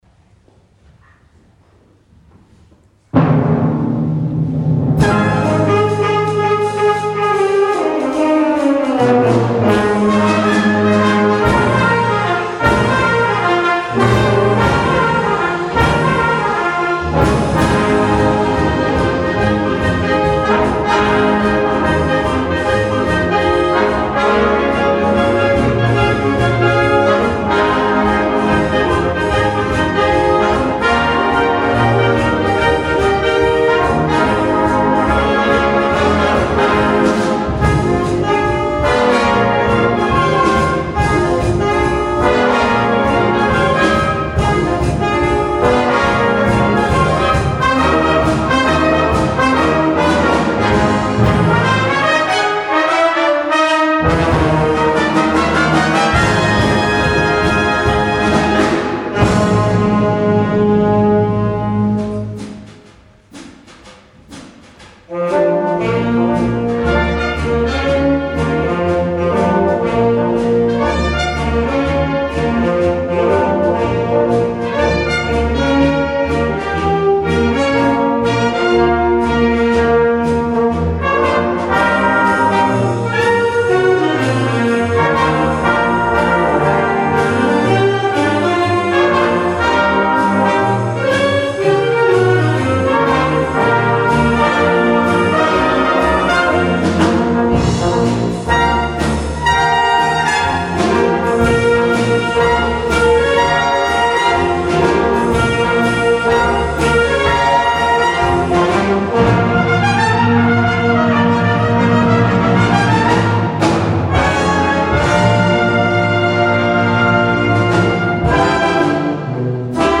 Jahreskonzert 2020
Musikgesellschaft St. Moritz